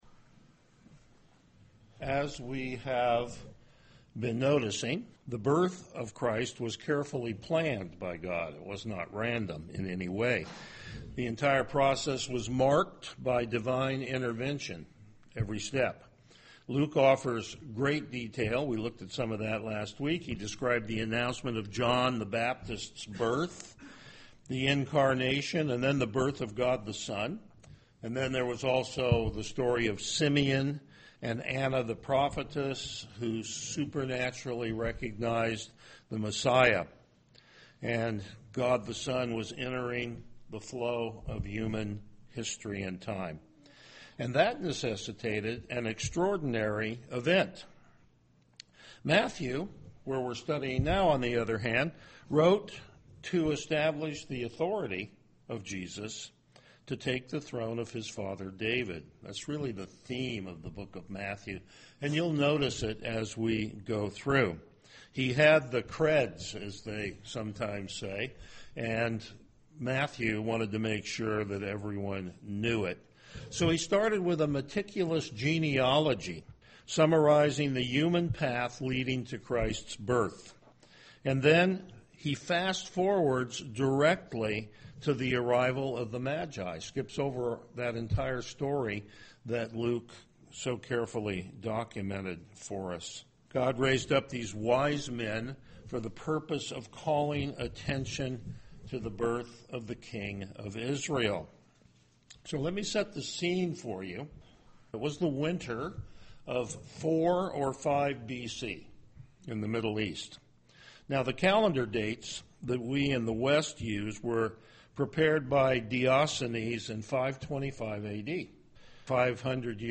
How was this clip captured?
Passage: Matthew 2:1-12 Service Type: Morning Worship